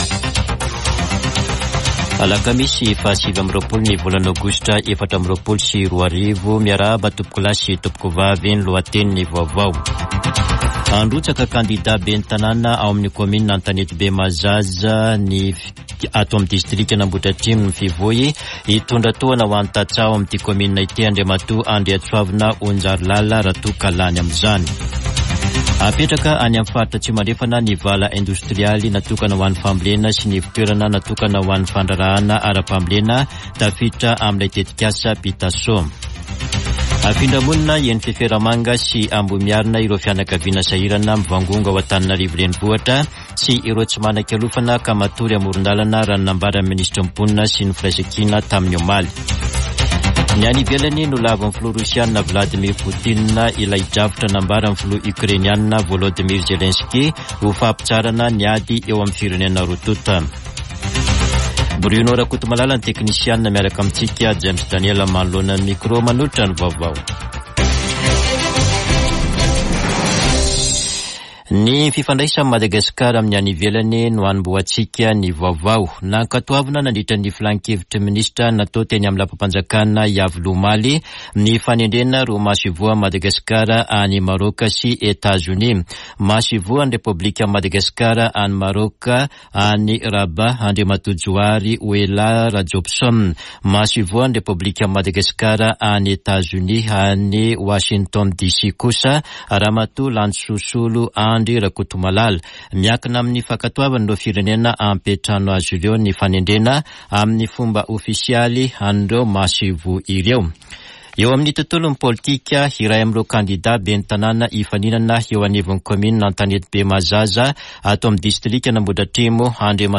[Vaovao maraina] Alakamisy 29 aogositra 2024